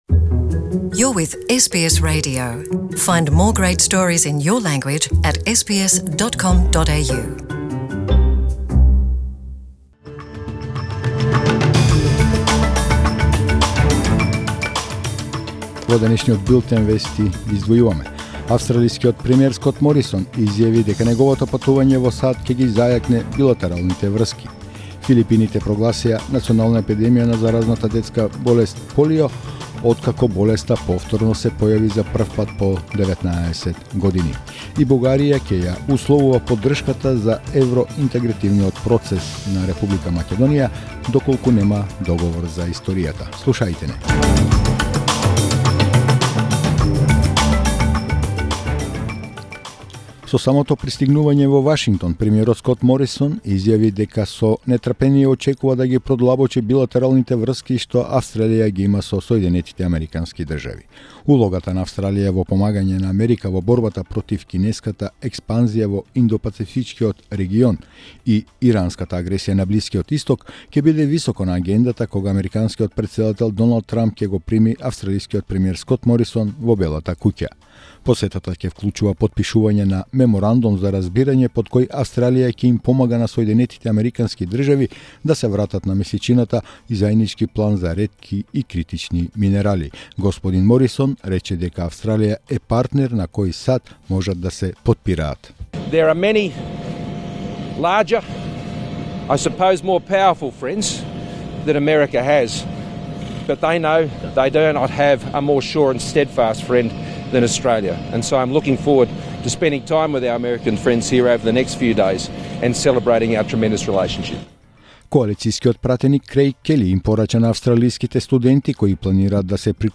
SBS Macedonian News 20 September 2019